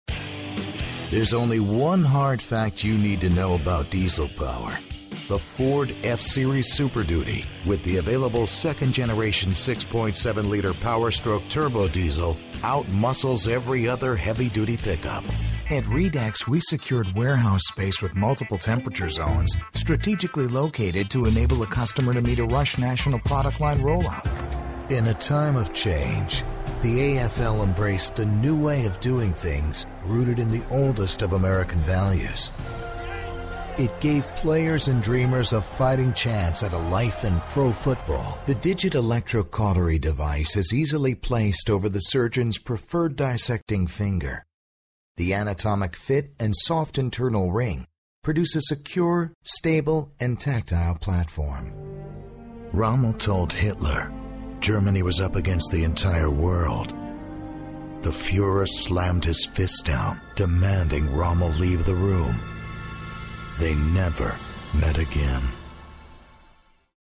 compelling, dynamic, velvety, contemplative, friendly, sexy, romantic, buttery, adaptable and easy to work with
englisch (us)
Sprechprobe: Industrie (Muttersprache):